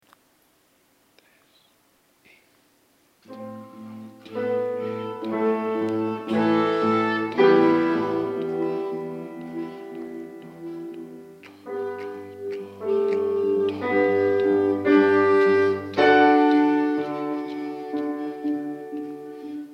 - Tonalidad: Mi menor
Interpretaciones en directo.
4º ritmo reguladores
4o_ritmo-reguladores.MP3